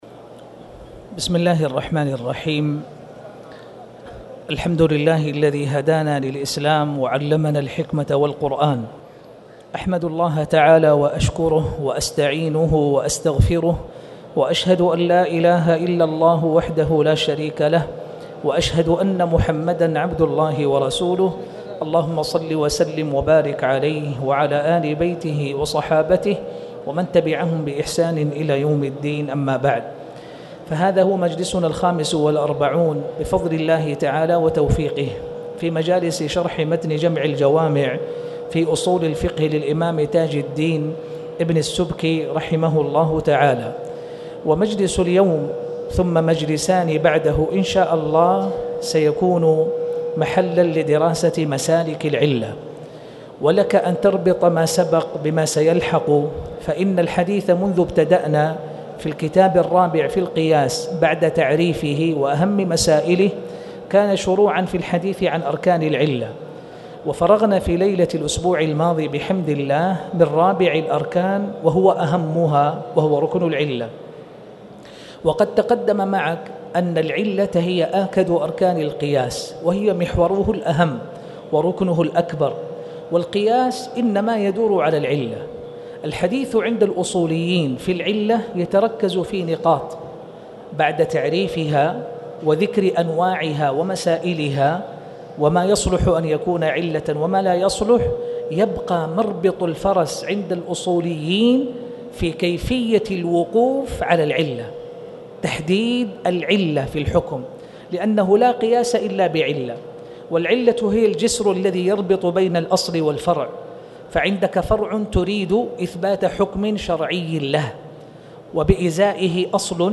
تاريخ النشر ٢٢ ربيع الأول ١٤٣٨ هـ المكان: المسجد الحرام الشيخ